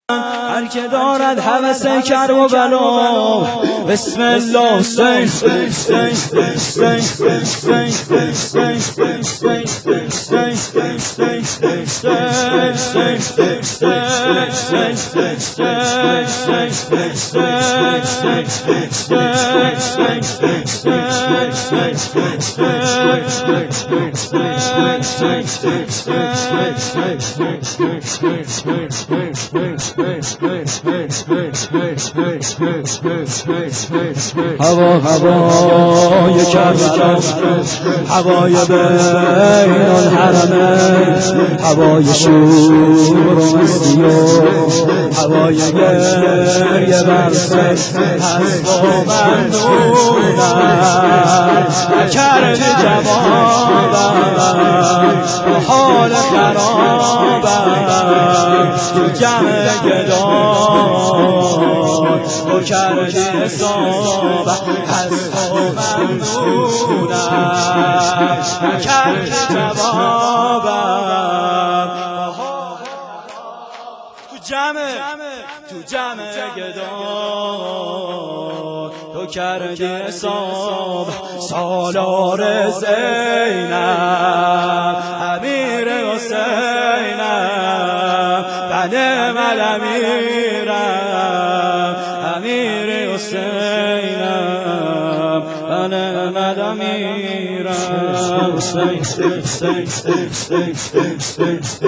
شور دوم